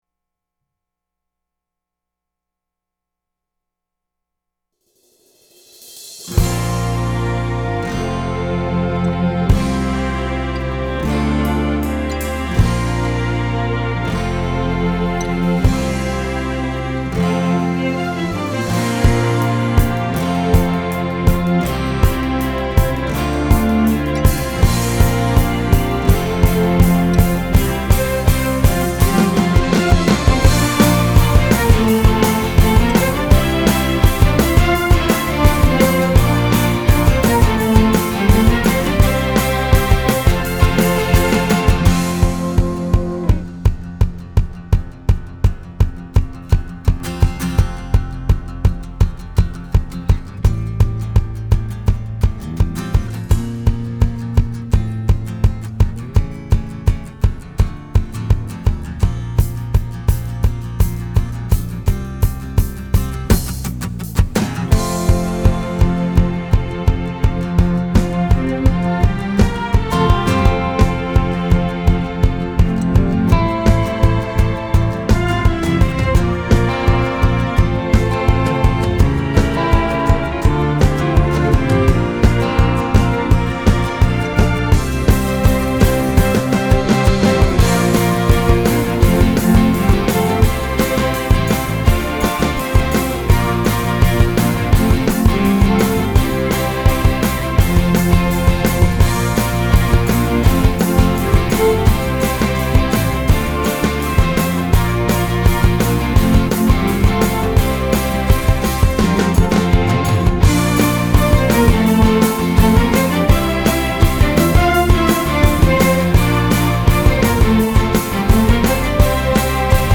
新的事将要成就伴奏.mp3